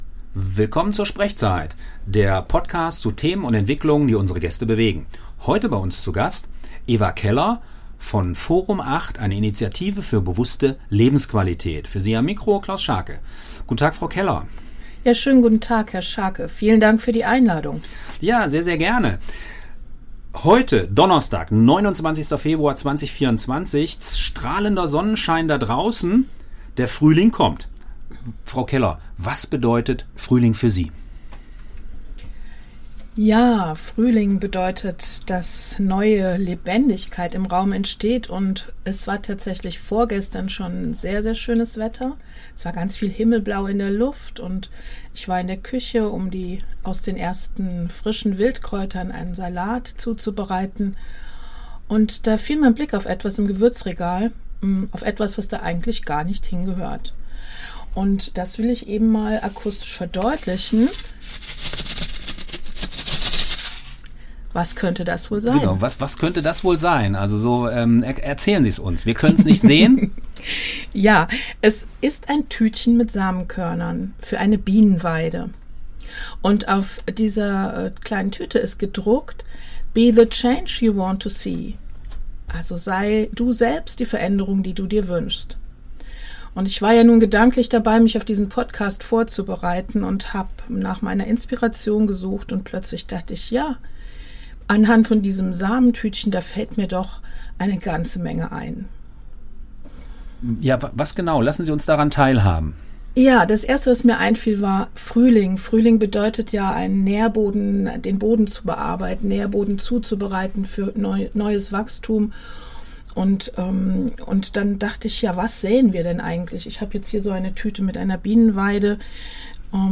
Dieser SprechZeit-Podcast wurde beim Freien Radio Kassel aufgezeichnet.